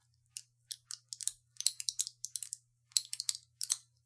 dry leaves isolated crackling
描述：In the studio manipulating dry leaves. Devices + rode directional+5 gain
标签： crackling crack isolated leaves crunch dry
声道立体声